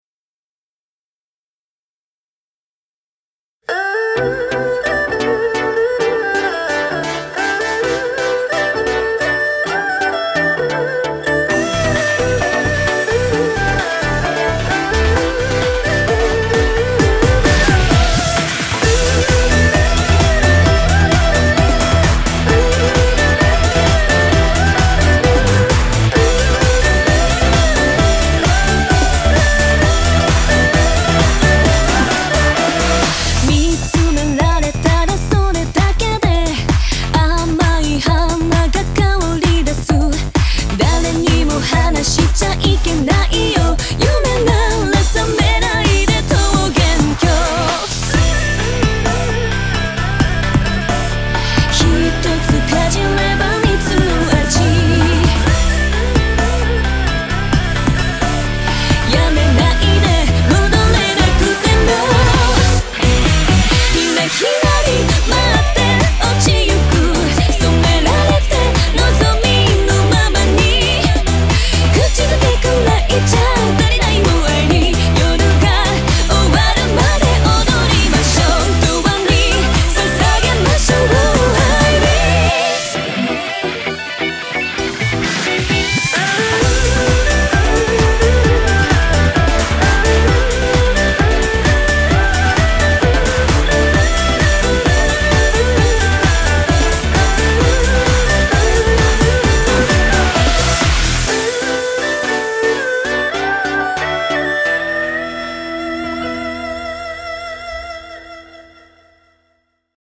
BPM83-131
Audio QualityPerfect (High Quality)